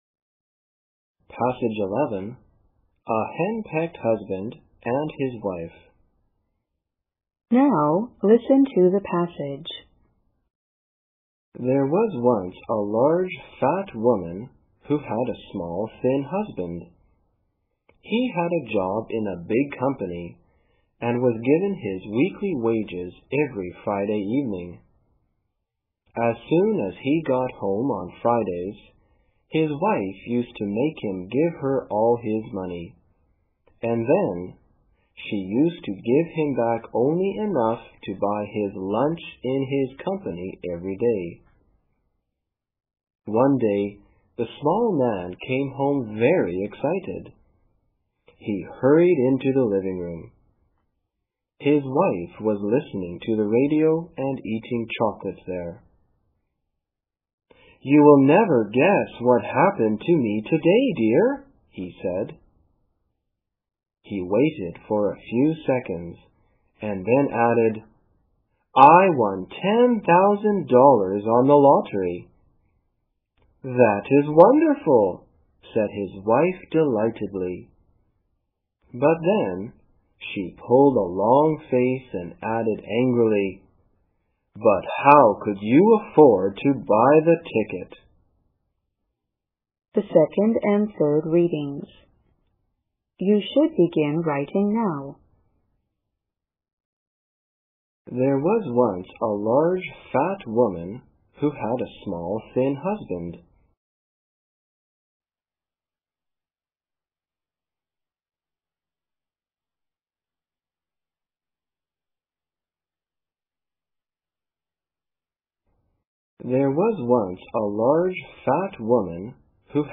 英语专业四级听写50篇(11):A Henpecked Husband and His Wife